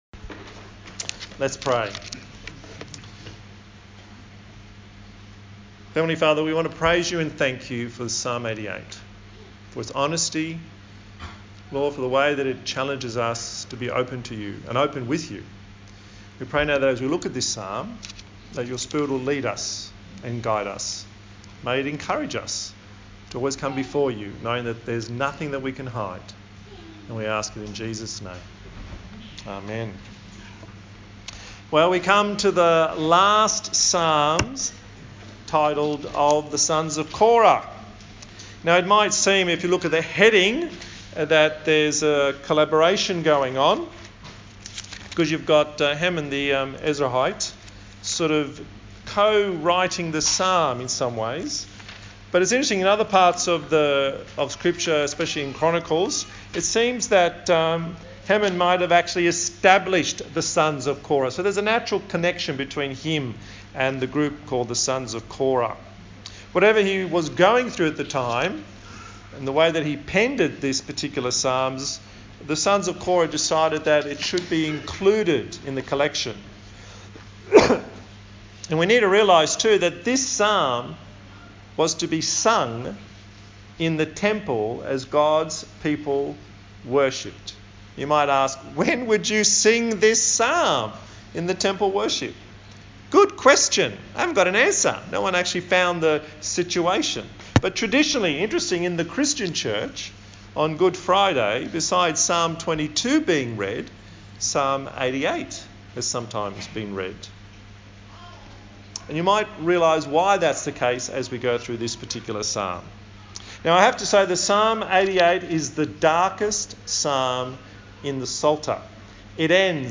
Psalms Passage: Psalm 88 Service Type: Sunday Morning